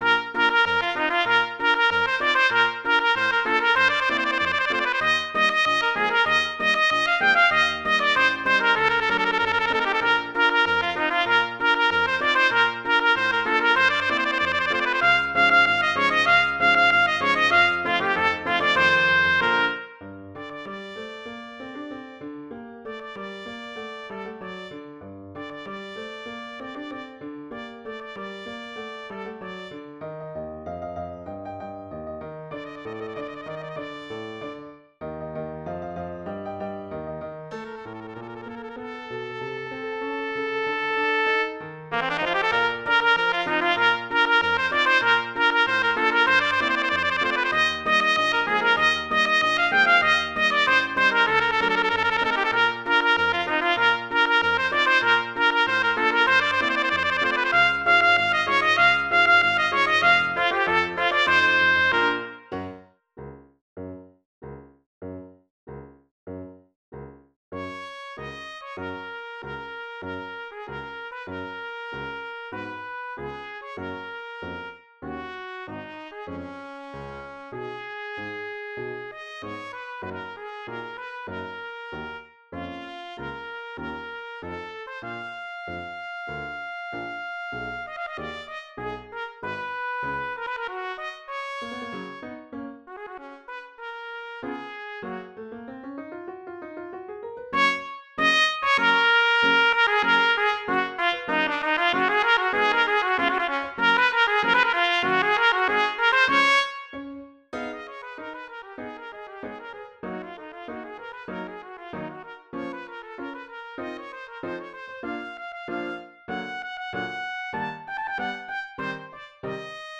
Voicing: Trumpet Solo